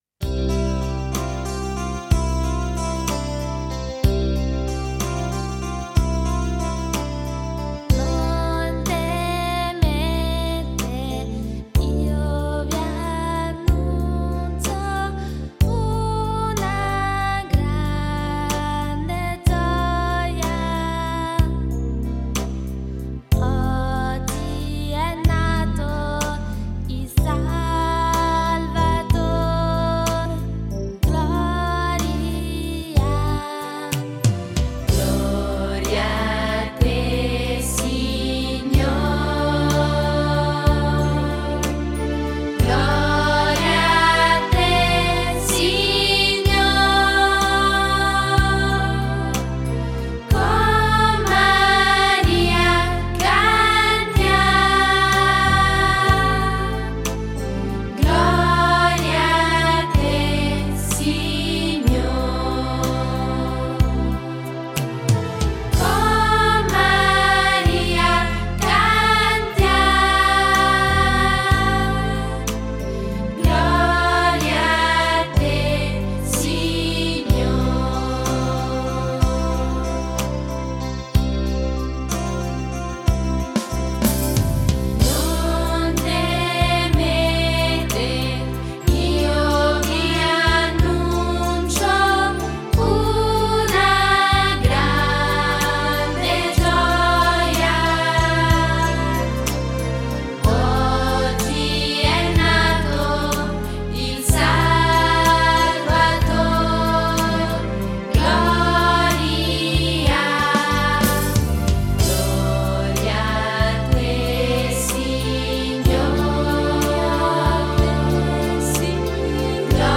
Canto per la Decina: Non temete, io vi annuncio